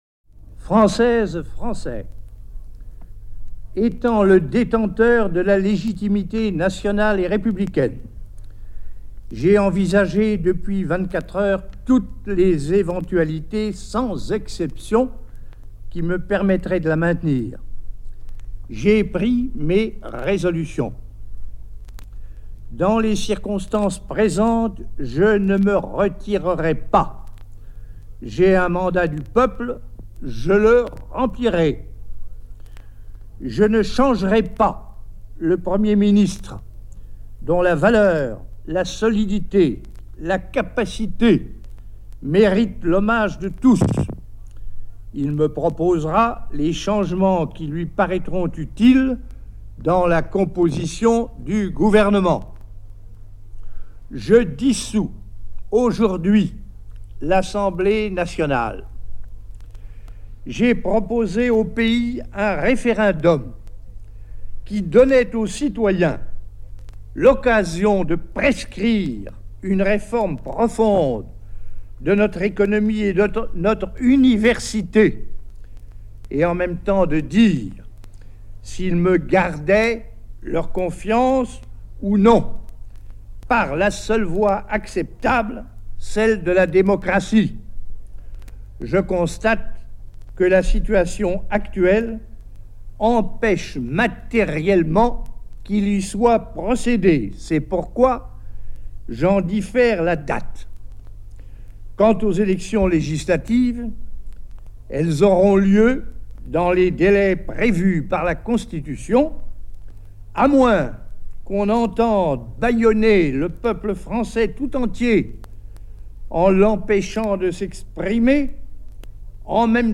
L’allocution prononcée par de Gaulle le 30 mai
30 mai 1968 (à 16 heures 30). — Allocution radiodiffusée du président de la République ; une heure plus tard, une gigantesque manifestation de soutien remonte les Champs-Élysées.